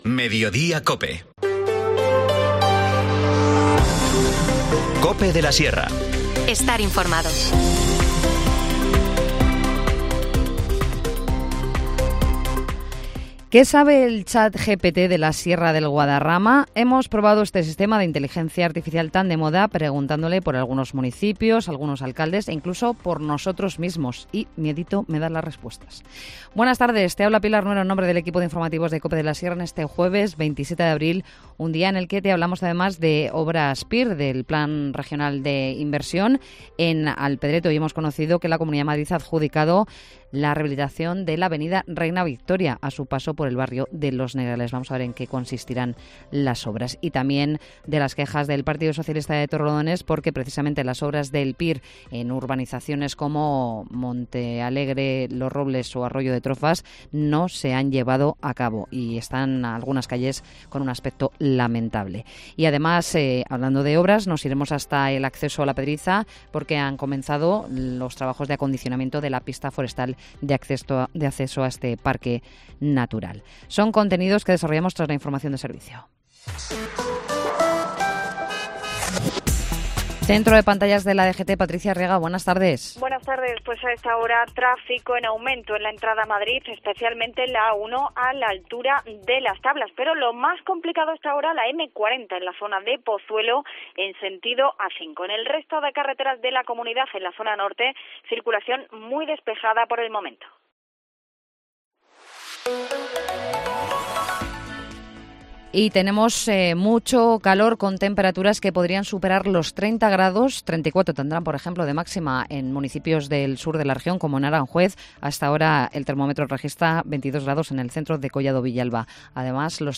Informativo Mediodía 27 abril